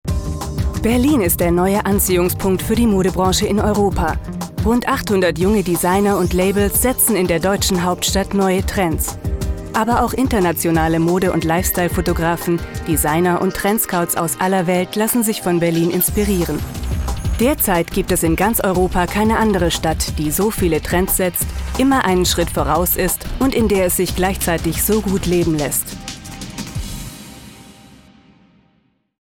Sprecherin aus Berlin mit vielseitig einsetzbarer Stimme - frisch/jugendlich - sachlich/seriös - sanft & warm.
Sprechprobe: Industrie (Muttersprache):